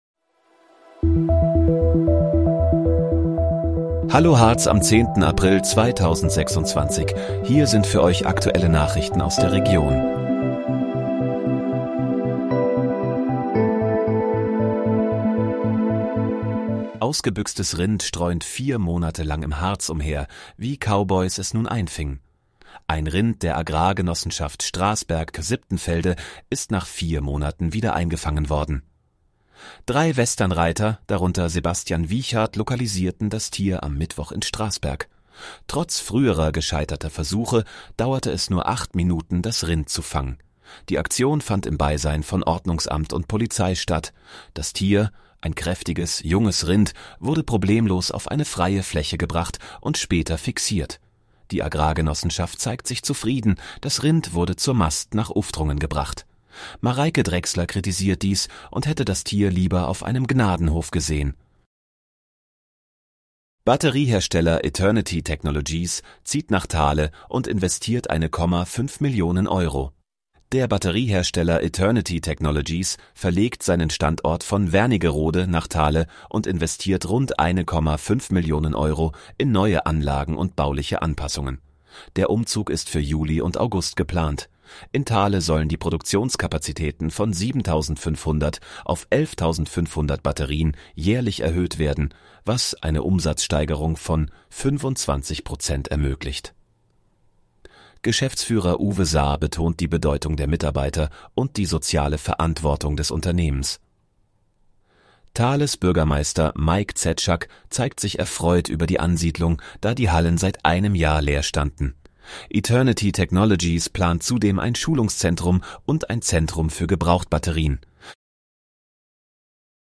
Hallo, Harz: Aktuelle Nachrichten vom 10.04.2026, erstellt mit KI-Unterstützung